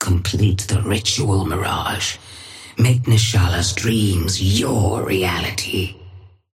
Sapphire Flame voice line - Complete the ritual, Mirage.
Patron_female_ally_mirage_start_02.mp3